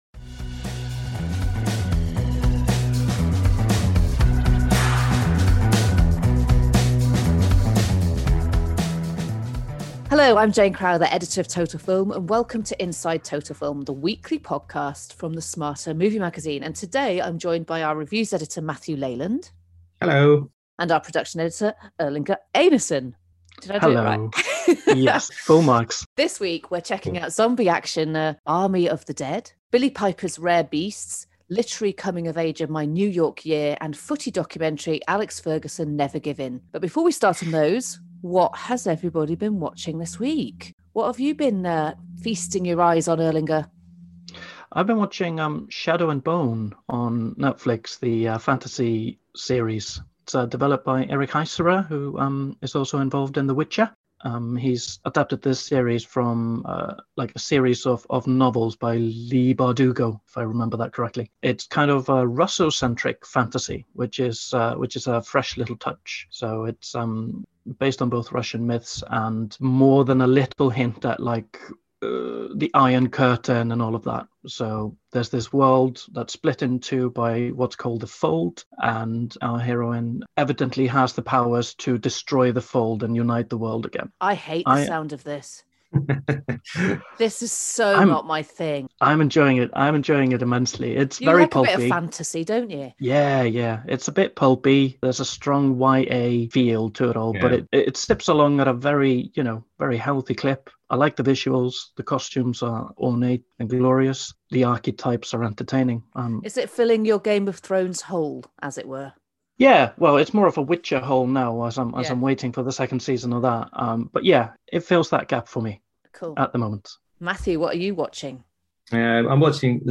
No animals interrupt this week.